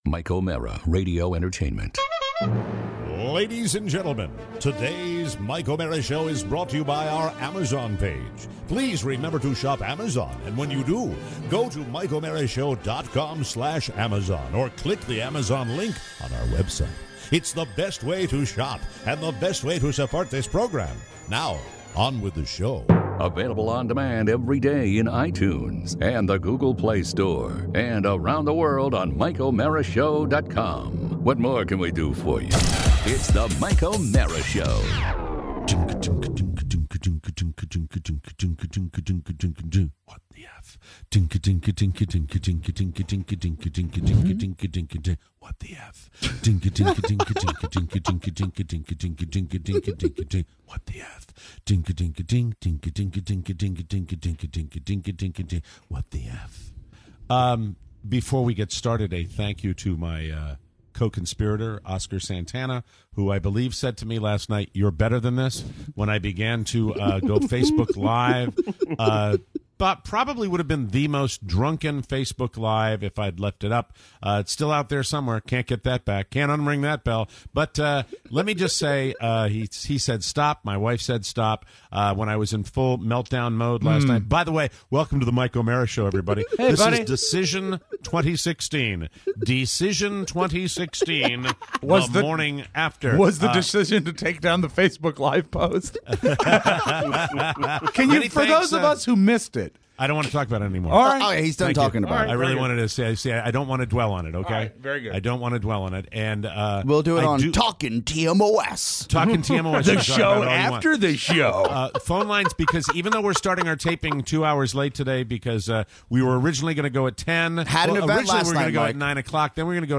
Today we reflect. We take some great calls